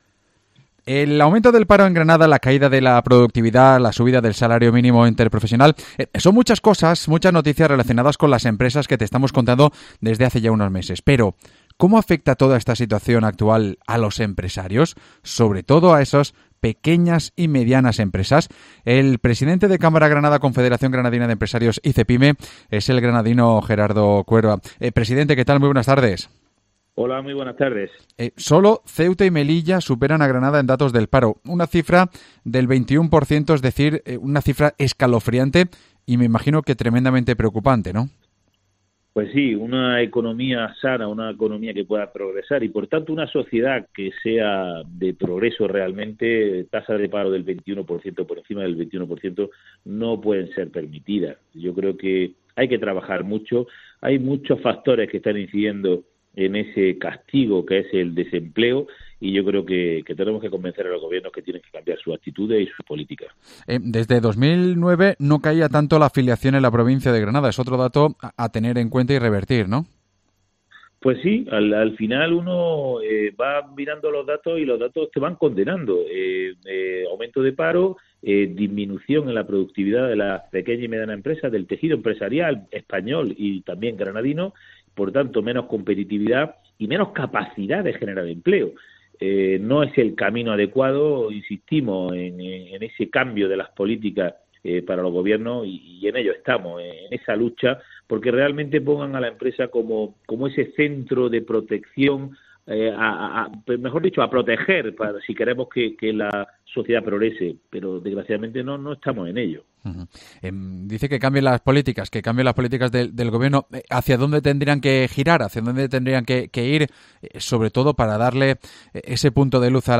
El presidente de CEPYME, Cámara de Comercio de Granada y CGE, el granadino Gerardo Cuerva , ha repasado en los micrófonos de COPE toda la actualidad empresarial y económica.